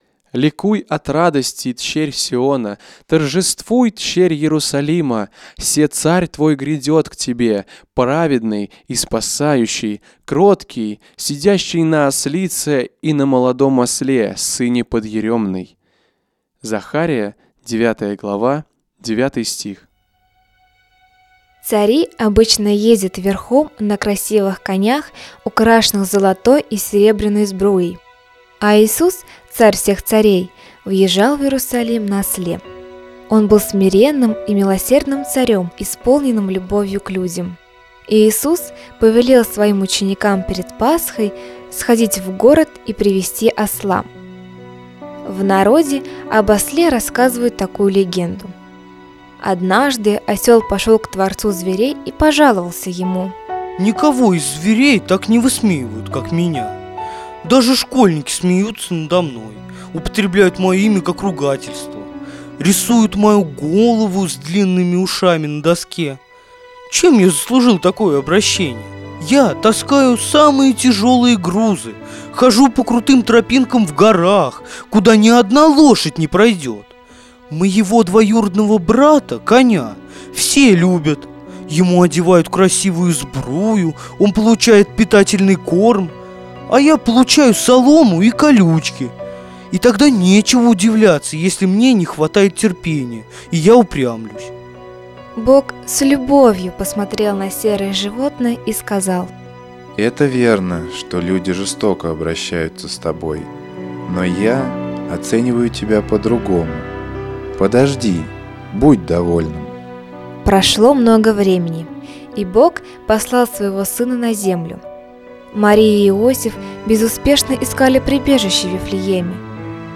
Ибо их есть Царство Небесное. Христианские детские рассказы
В проекте принимает участие молодёжь г.Пензы.